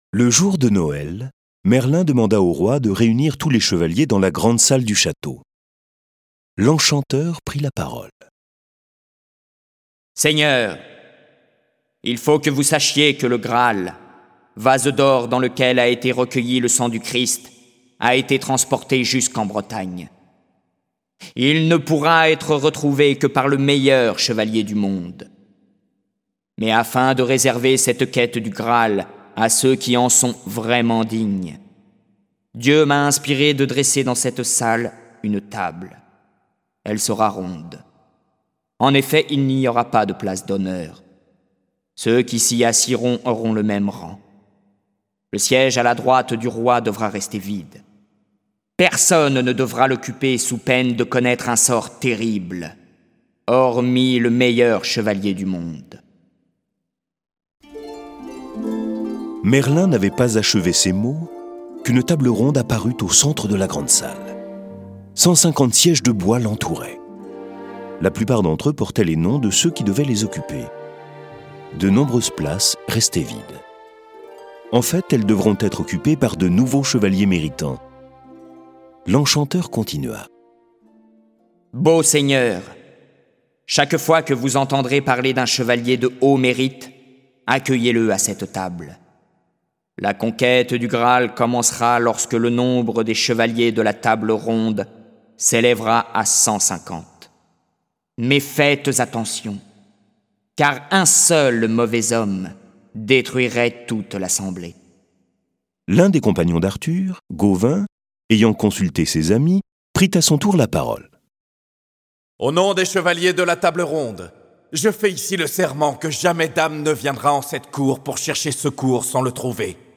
Diffusion distribution ebook et livre audio - Catalogue livres numériques
Le récit sonore de l'aventure de Merlin est animé par plusieurs voix et accompagnée de plus de trente morceaux de musique classique.
Le récit et les dialogues sont illustrés avec les musiques de Bizet, Charpentier, Chopin, Corelli, Debussy, Delibes, Dvorak, Grieg, Mendelssohn, Mozart, Pergolèse, Rameau, Tchaïkovski, Vivaldi.